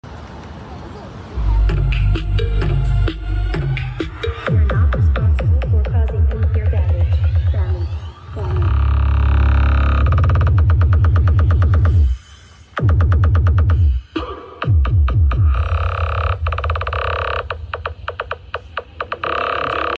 MAX Three-way subwoofer